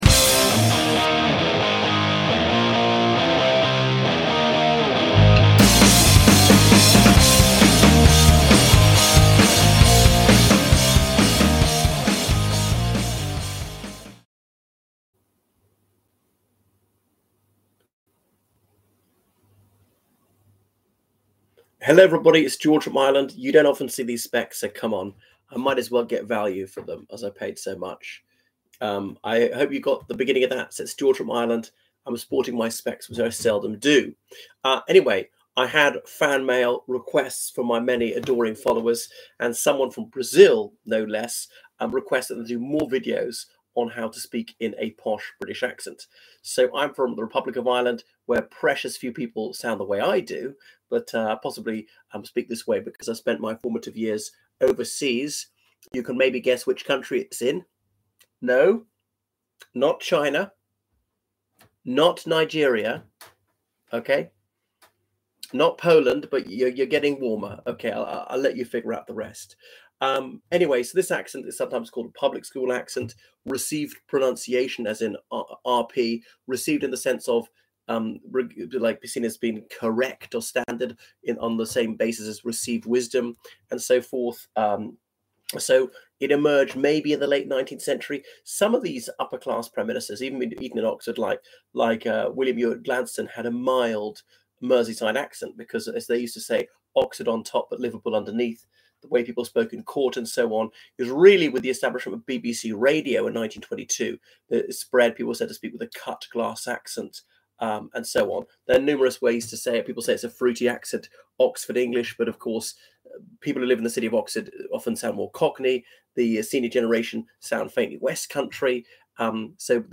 Podcast Episode 03721: Received Pronunciation – how to speak in an upper class British accent. This is posh public school